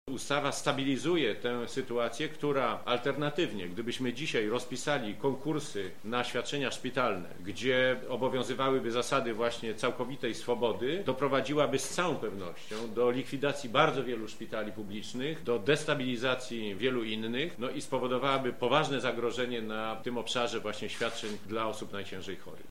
Jak mówi Konstanty Radziwiłł, alternatywą jest likwidacja części placówek: